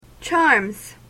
/tʃɑrmz(米国英語), tʃɑ:rmz(英国英語)/